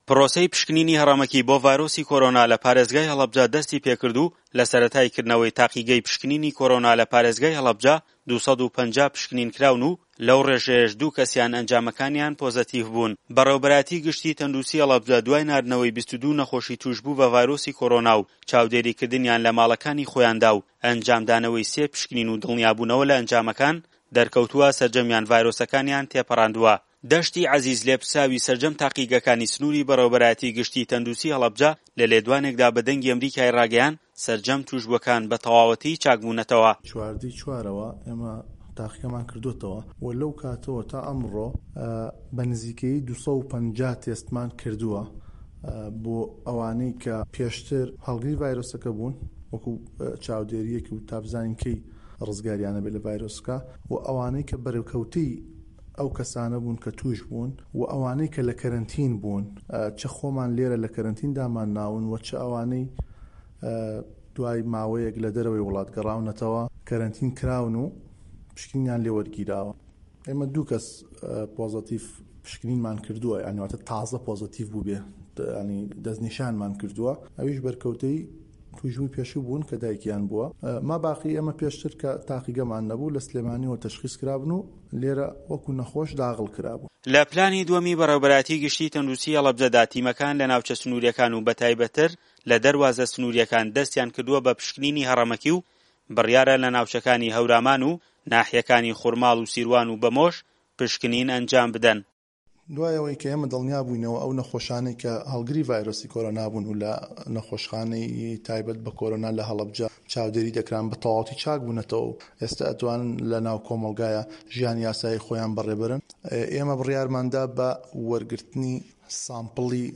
زانیاری زیاتر له‌ ده‌قی راپورته‌كه‌دایه‌